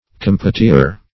Search Result for " compotier" : The Collaborative International Dictionary of English v.0.48: Compotier \Com`po`tier"\ (k[^o]N`p[-o]`ty[=a]"), n.; pl.